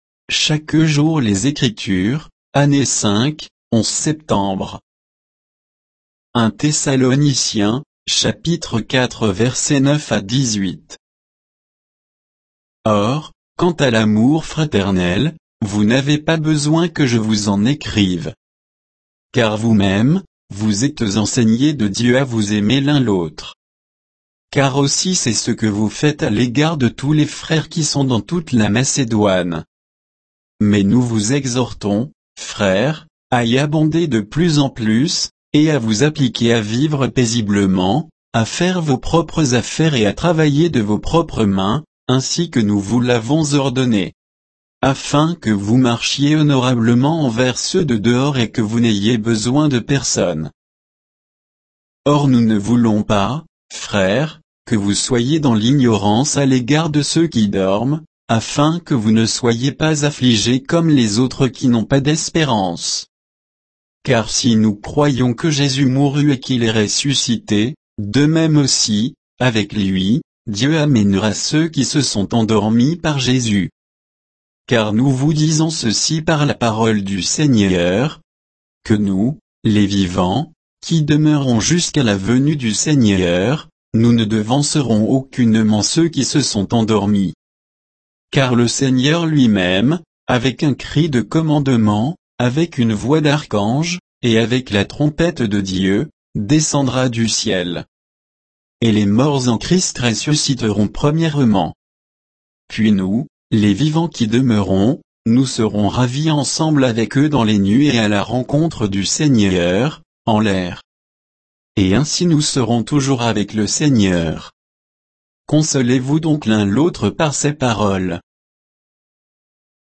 Méditation quoditienne de Chaque jour les Écritures sur 1 Thessaloniciens 4